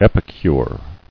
[ep·i·cure]